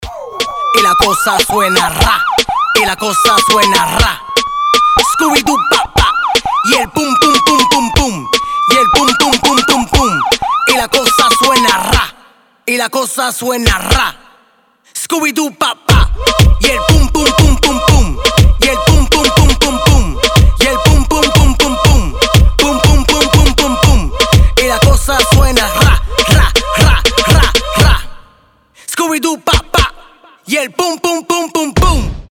ритмичные
Moombahton
Reggaeton
полицейская сирена
Оригинальный ритмичный рингтон в стиле мумбатон.